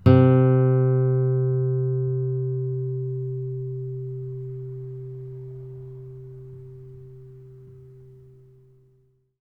bass-04.wav